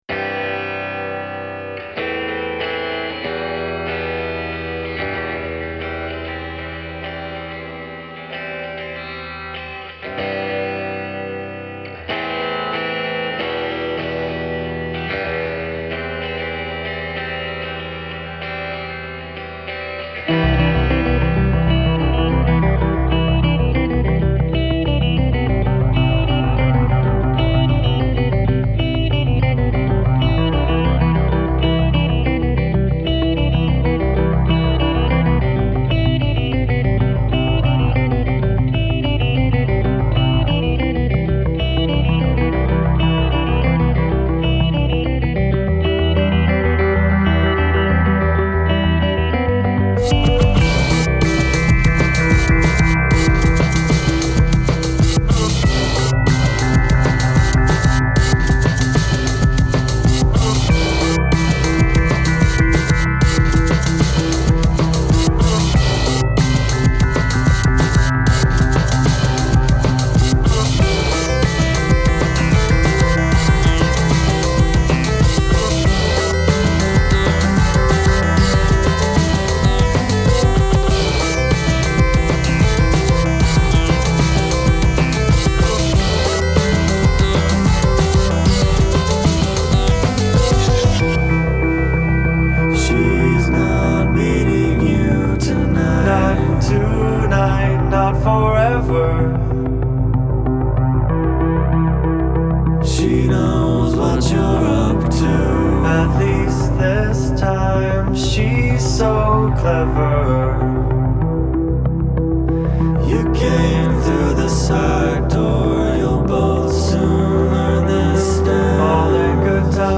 3 Aug LA's Electro Bend